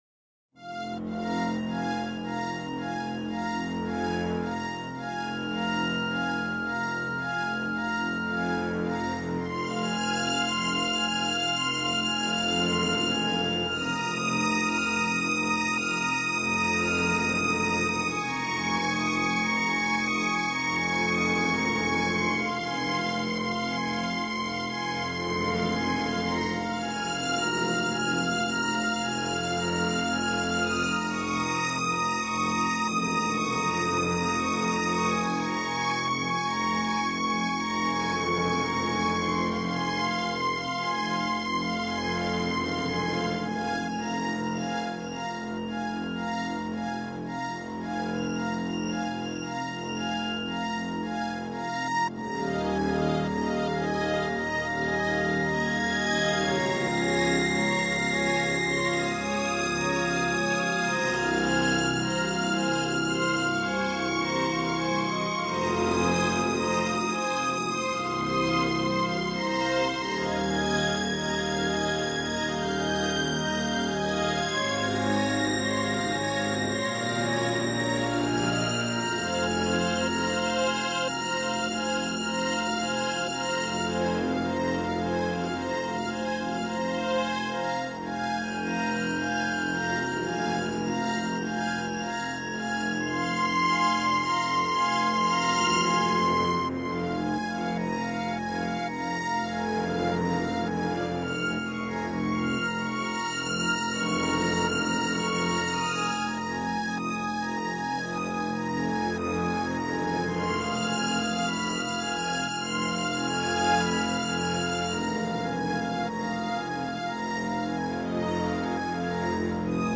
BGM Music